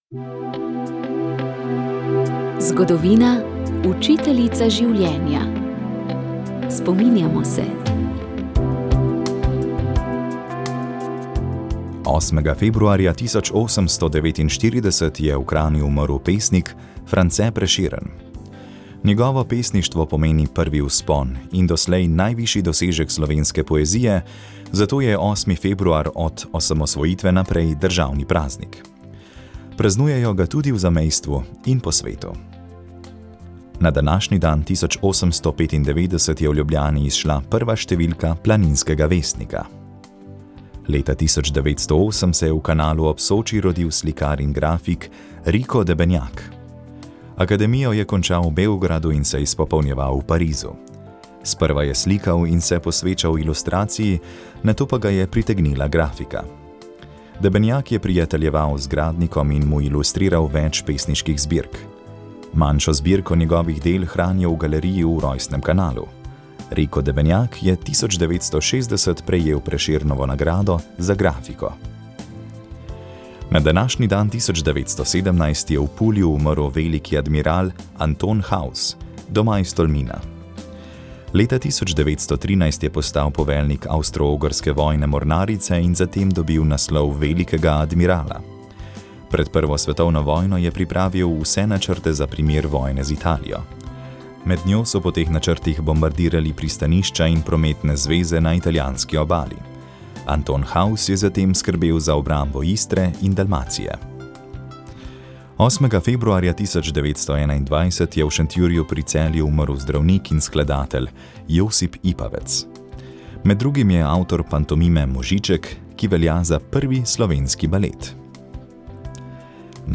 Turno smučanje že nekaj let doživlja nesluten razmah. O tej pristni obliki zimskega obiskovanja gorskega sveta, ki s seboj prinaša tudi negativne plati, smo se v Doživetjih narave pogovarjali